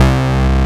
BASS06  01-L.wav